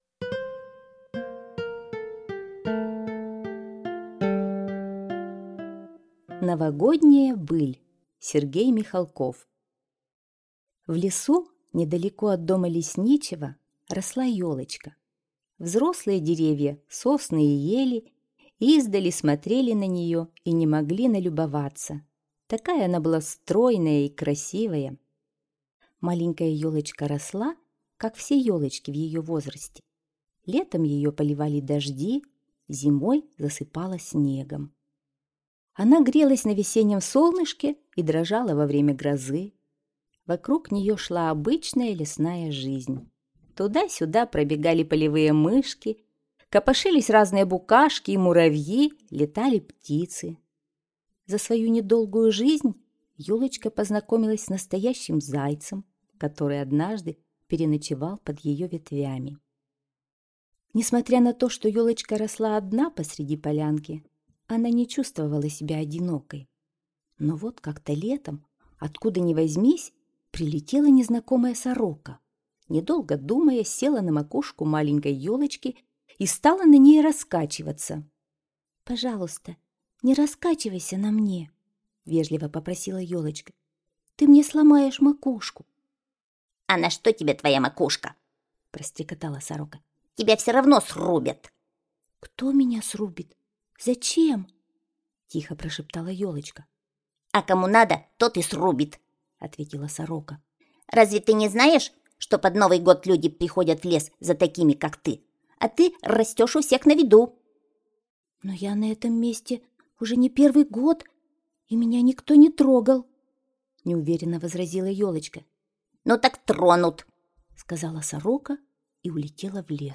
Новогодняя быль - аудиосказка Михалкова С.В. Однажды незнакомая сорока сказала ёлочке, что зимой её срубят люди.